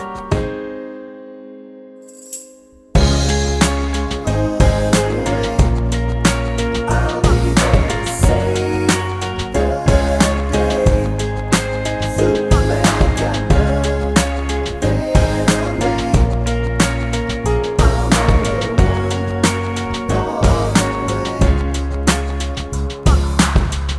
Professional Backing Tracks